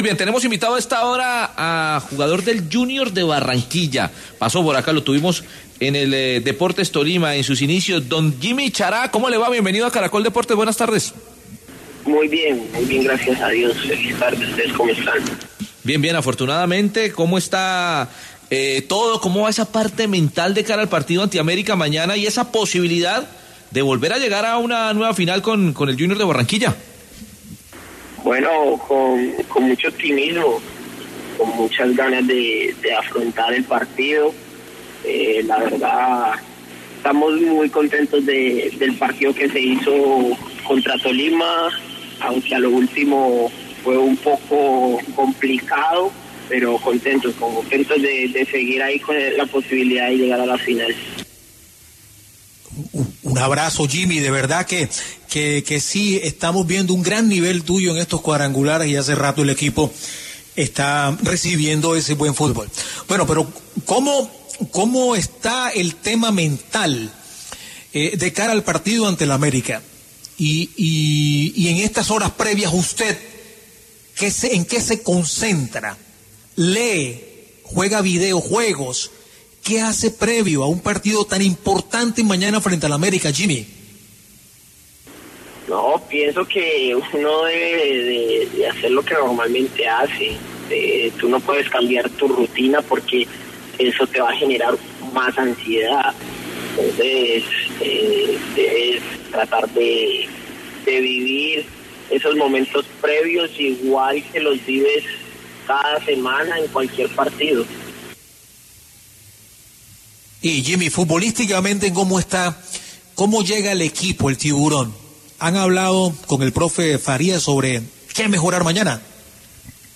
Justamente sobre la actualidad y las aspiraciones del conjunto atlanticense habló Yimmi Chará en Deportes Caracol Sábado. El experimentado atacante reconoció que se han enfocado en recuperarse físicamente y a partir de allí preparar los partidos.